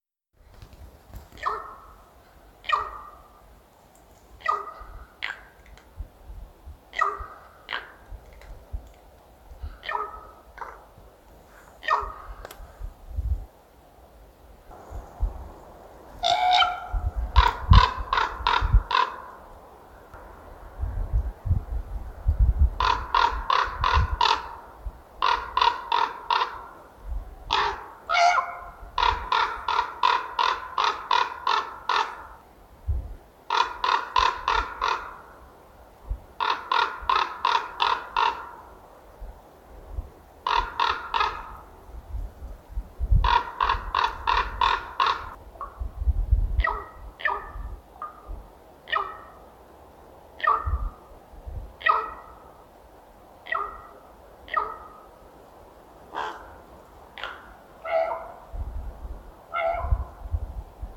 Fuglelyd fra "rar fugl"
Dette er klunkelyder fra en ravn.
rar_fugl.mp3